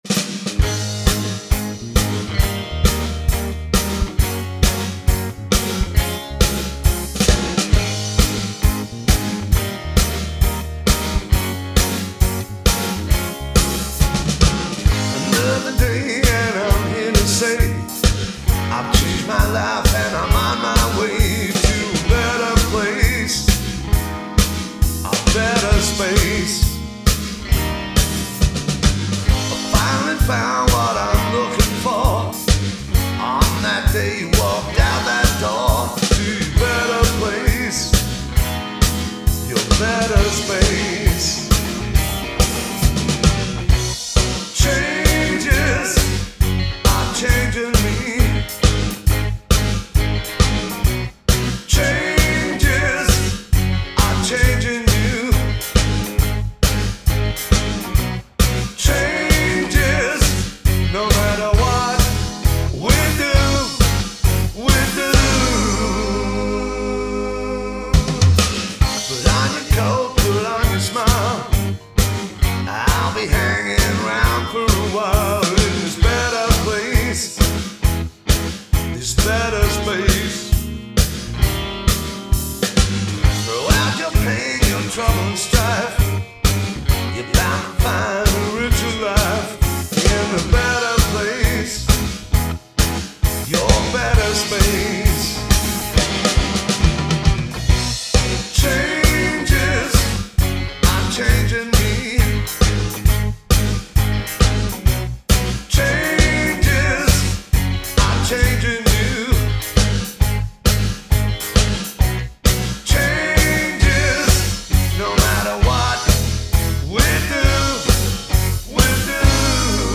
Americana